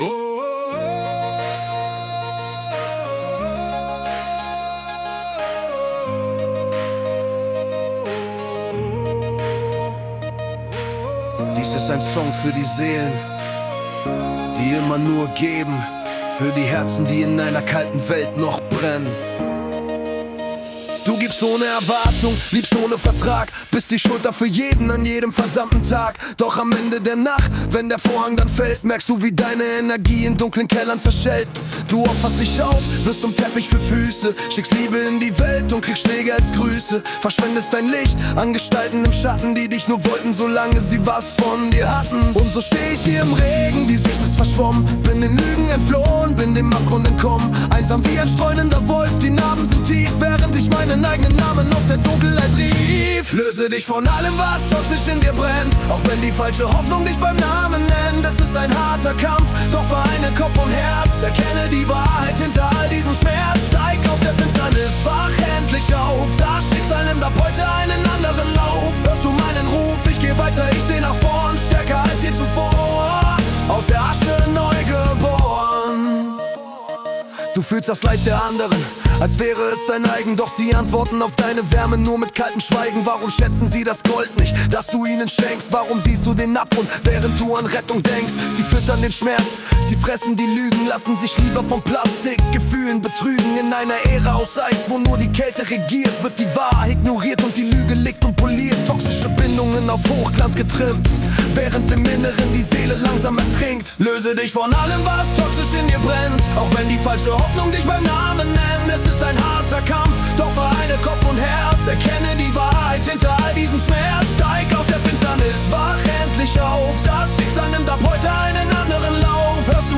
Motivation-Song